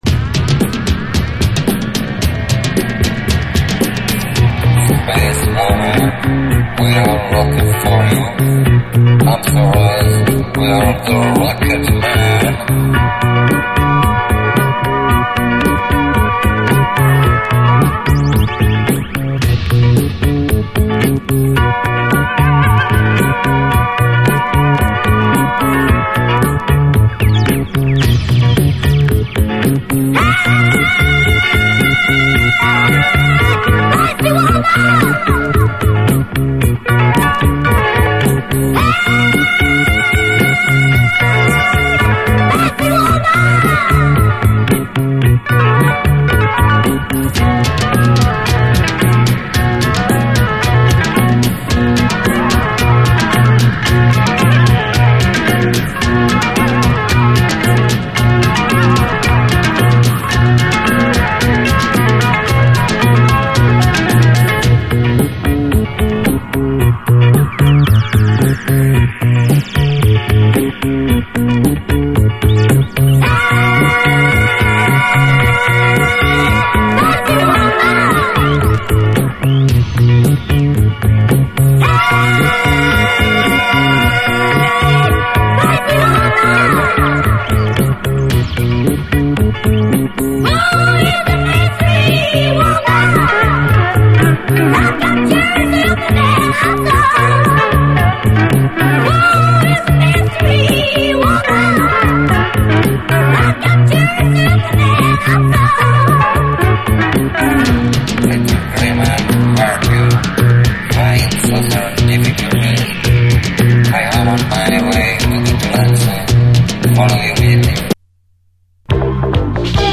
SOUL, 70's～ SOUL, DISCO, WORLD